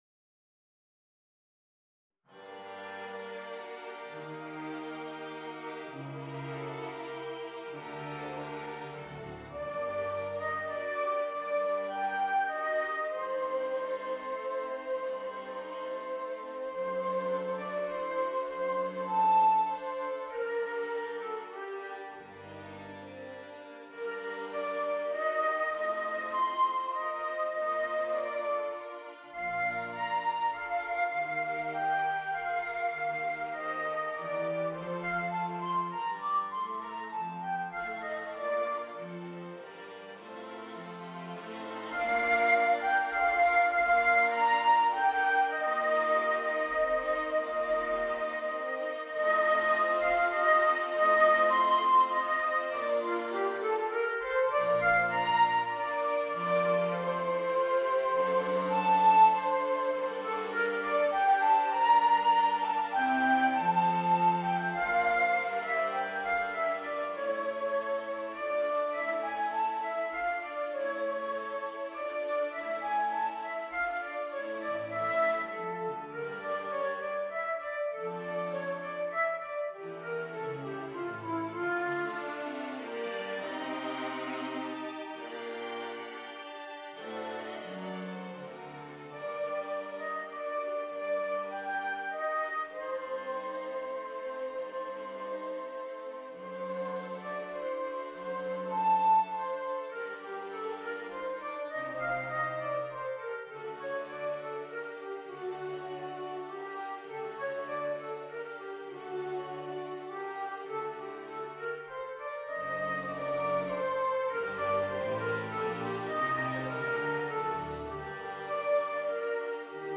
Voicing: Flute and String Quartet